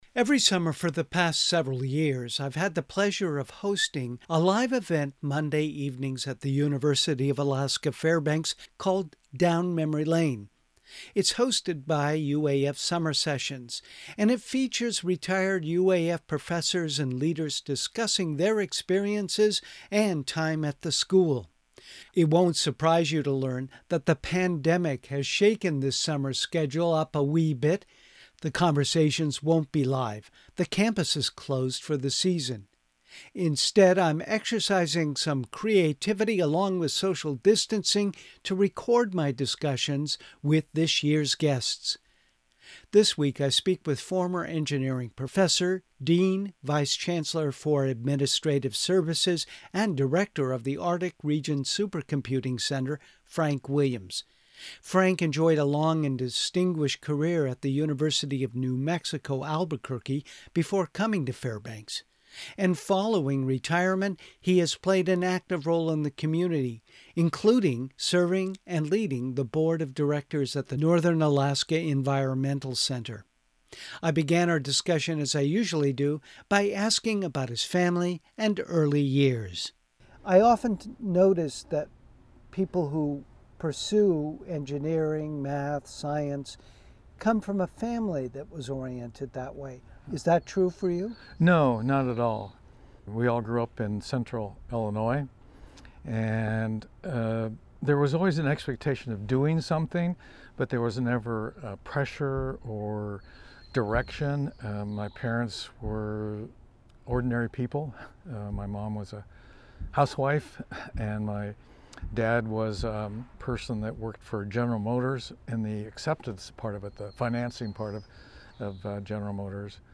The conversations won’t be live.
Instead, I’m exercising some creativity, along with social distancing, to record my discussions with this year’s guests.